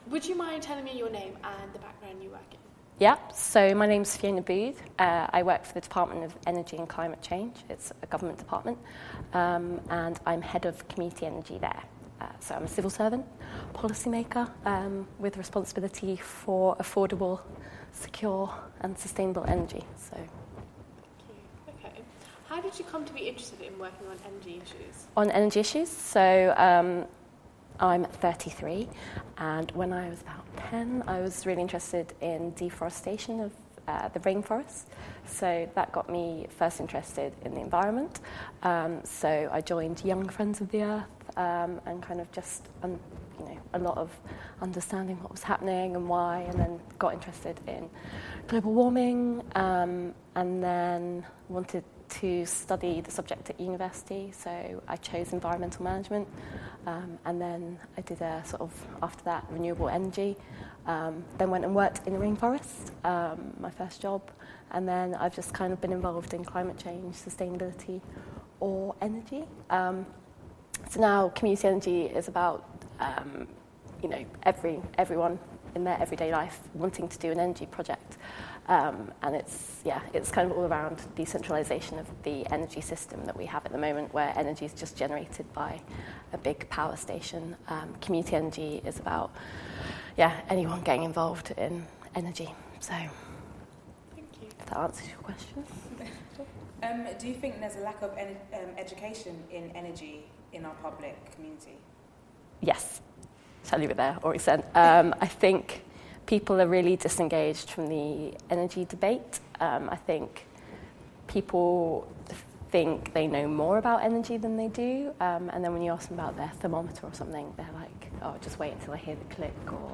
An Energy Generation interview